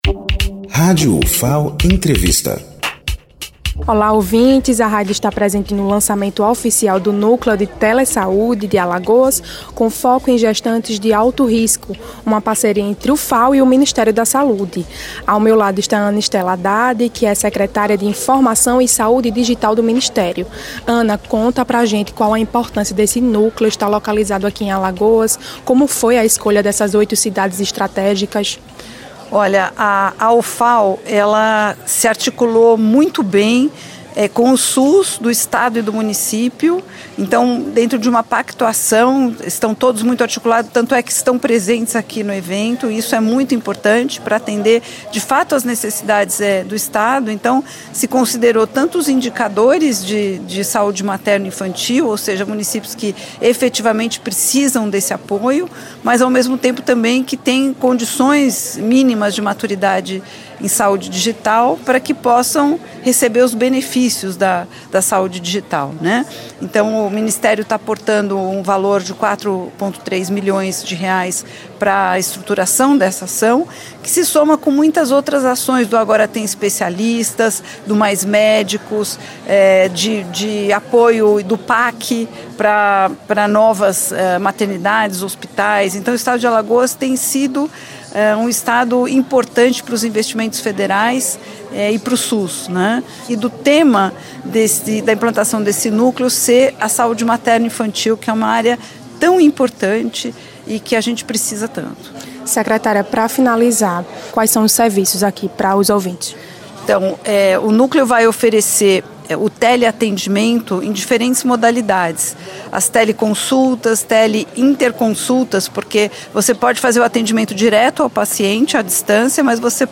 Entrevista com Ana Estela Haddad, secretária de informação e saúde digital do Ministério da Saúde.